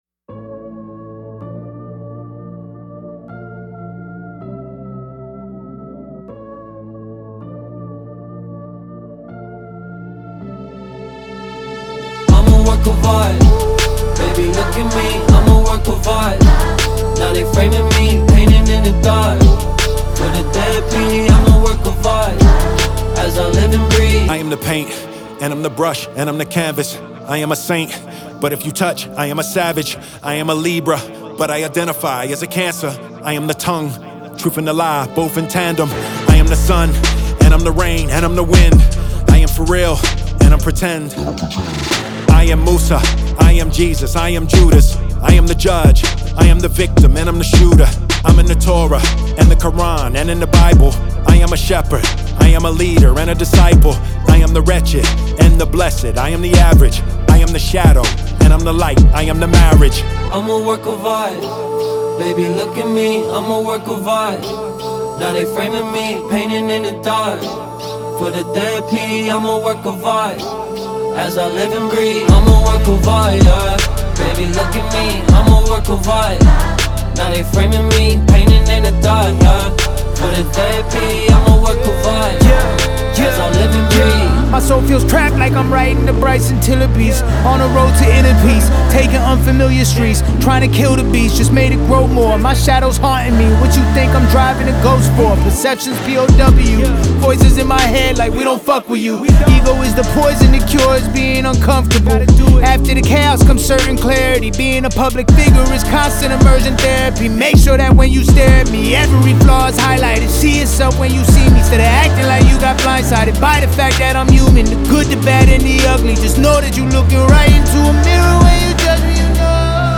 • Жанр: Rap, Hip-Hop